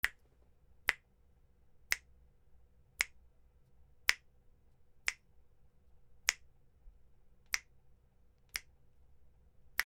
/ G｜音を出すもの / G-50 その他 手をたたく　体
指を鳴らす
C414